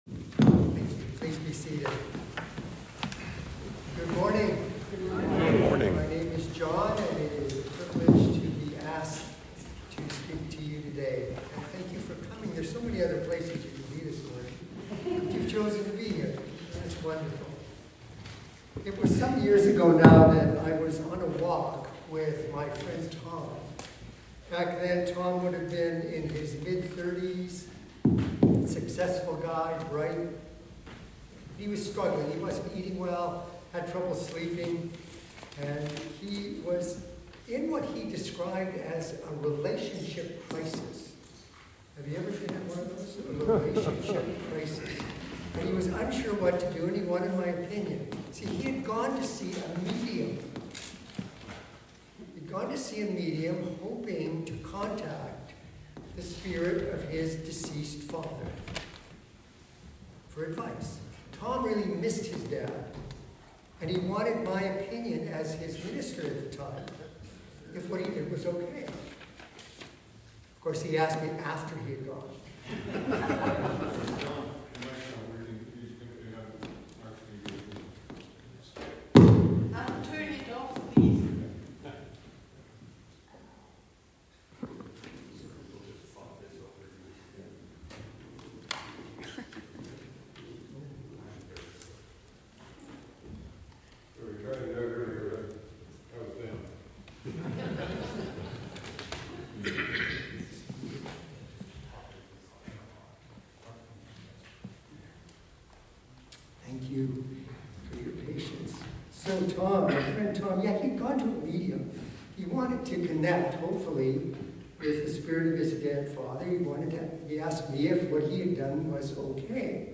Sermon 17 November 2024